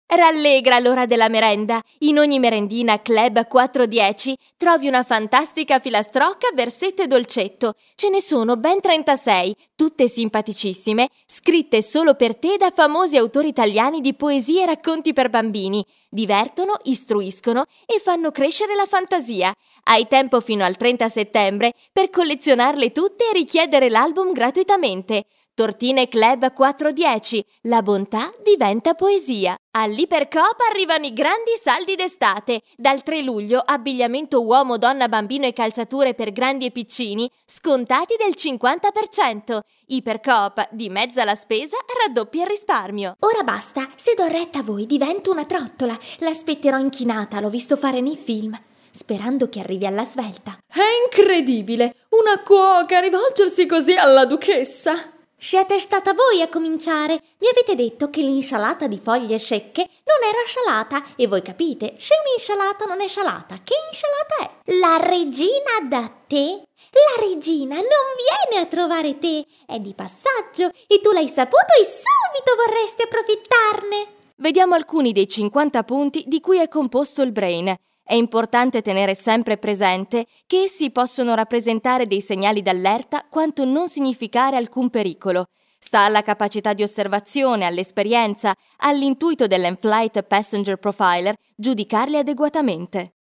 Kein Dialekt
Sprechprobe: Werbung (Muttersprache):